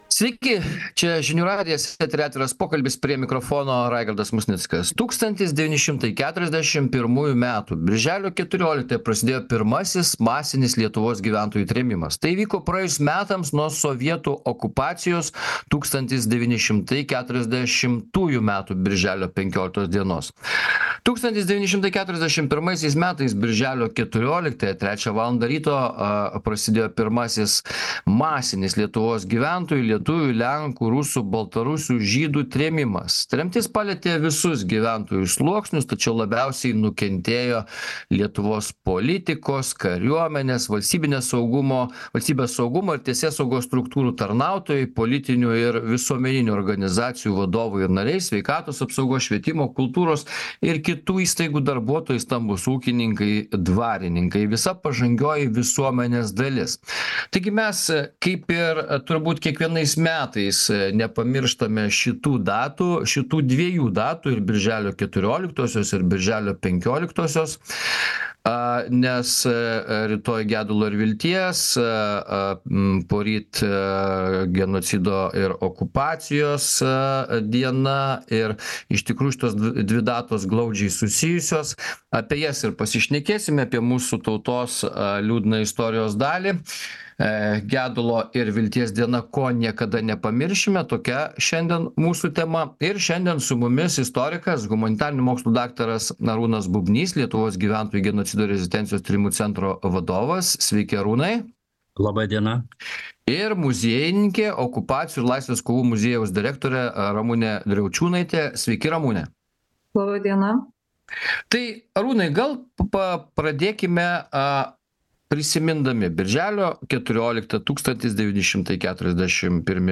Diskutuoja